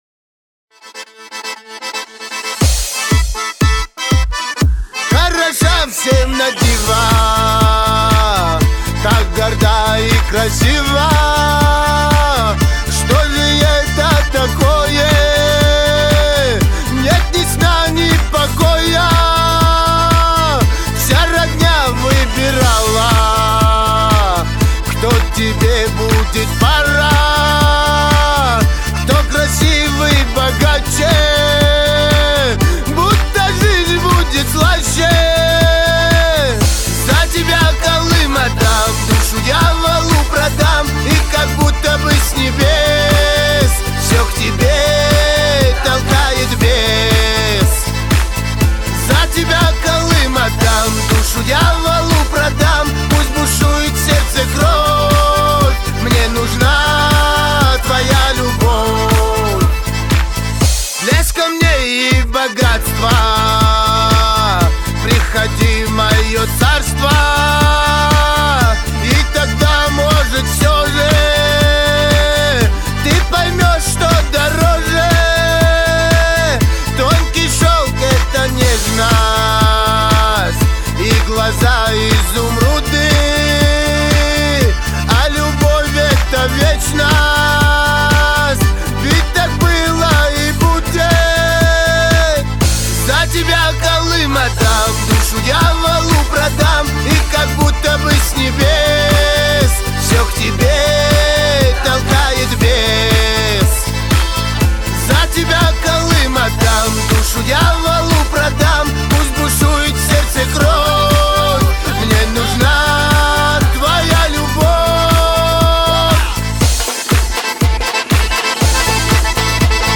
весёленько